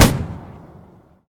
mg-shot-8.ogg